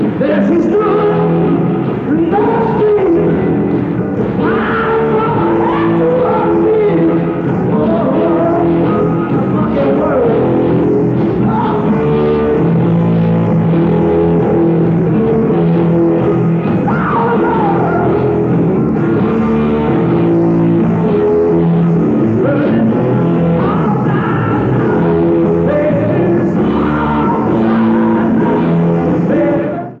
Format/Rating/Source: CD - G - Audience
Comments: Horrible audience recording.
Sound Samples (Compression Added):